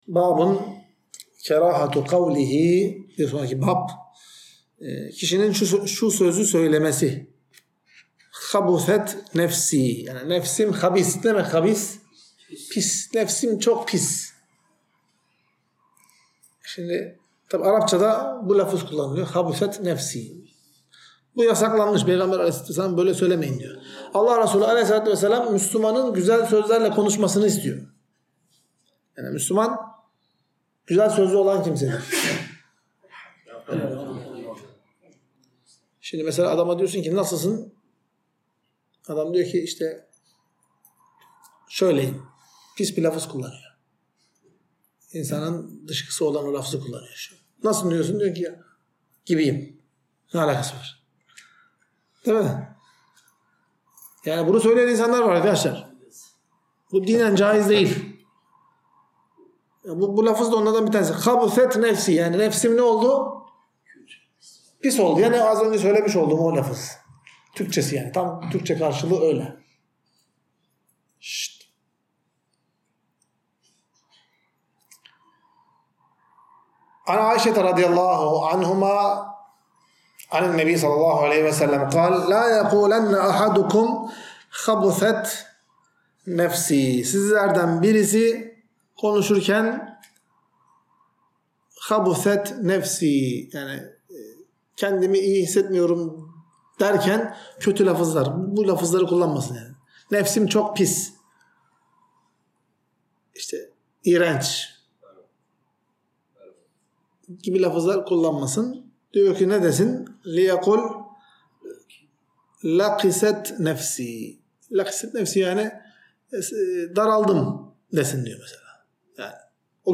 Ders - 75.